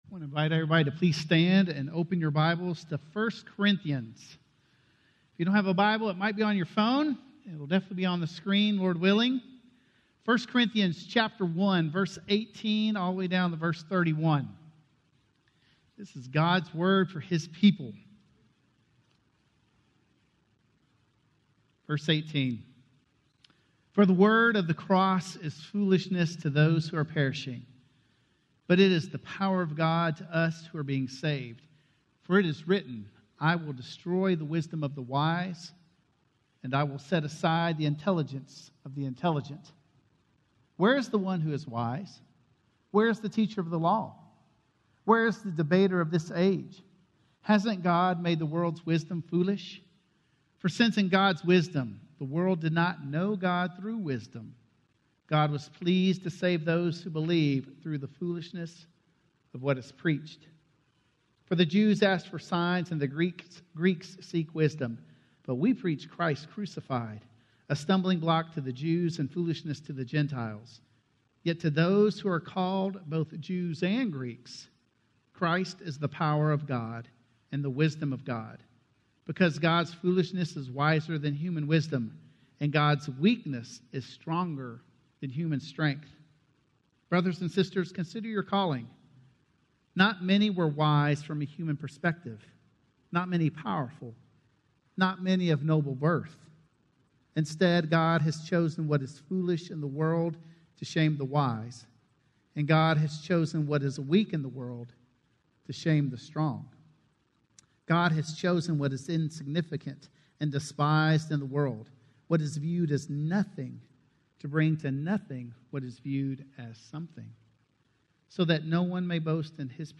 Finding Our Way - Sermon - Woodbine